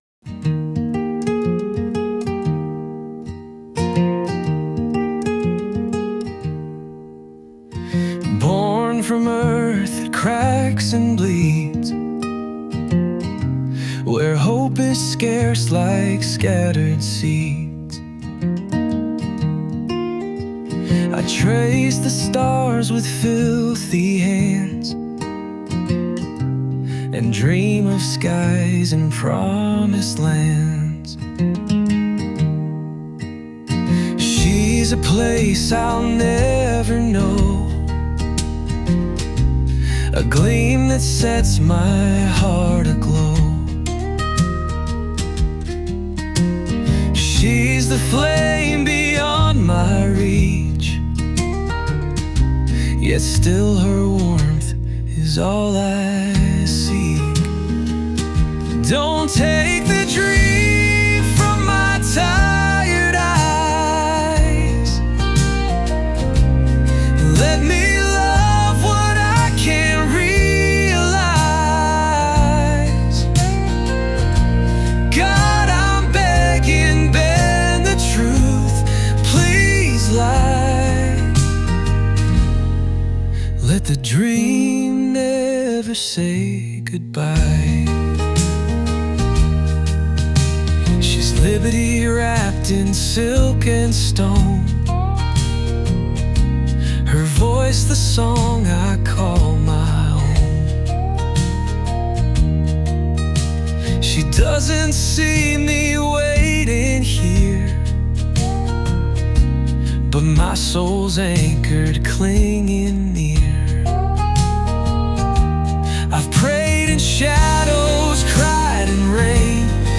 Country, Folk